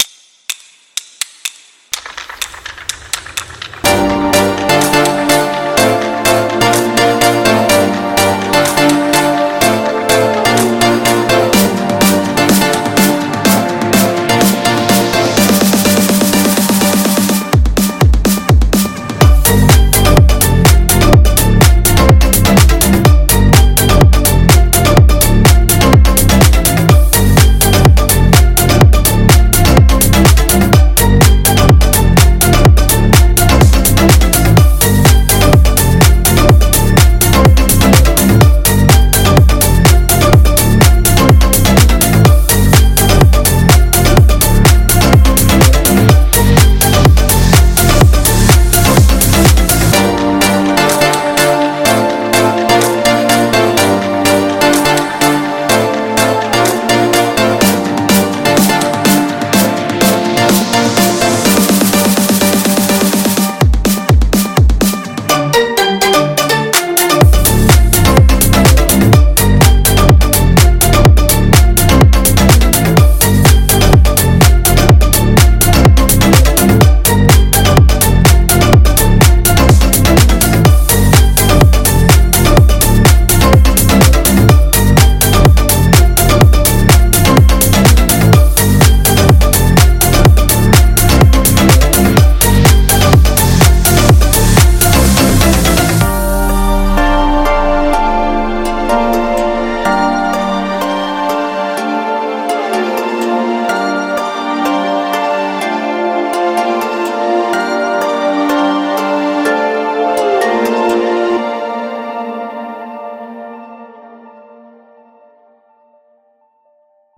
Audio QualityPerfect (High Quality)
BPM: 125